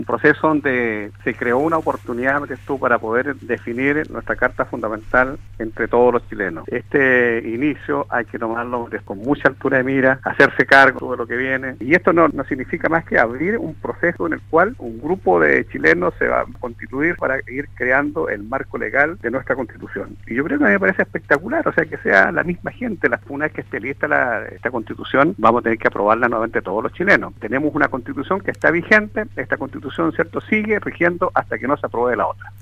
En conversación con Radio Sago, el alcalde de Osorno, Jaime Bertín se refirió al resultado del Plebiscito y que dejó a la opción Apruebo como la elegida por la ciudadanía.